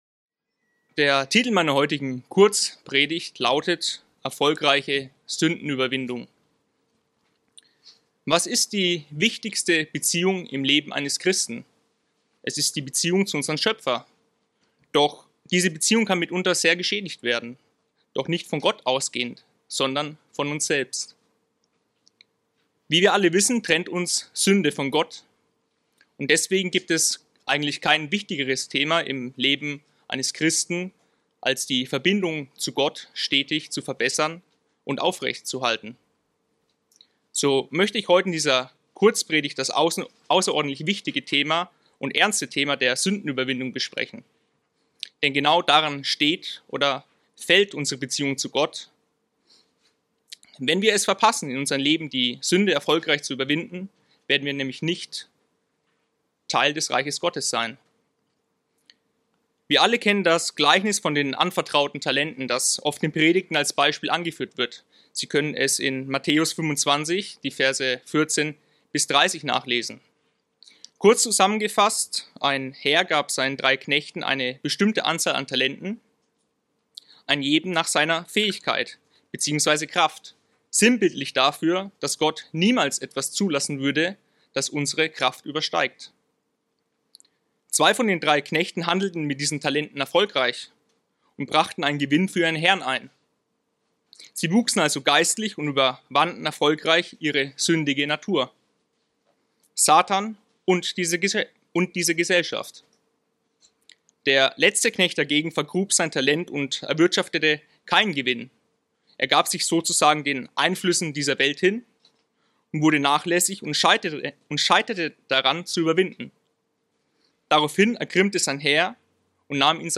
Diese Kurzpredigt zeigt grundlegende Aspekte der erfolgreichen Sündenüberwindung auf.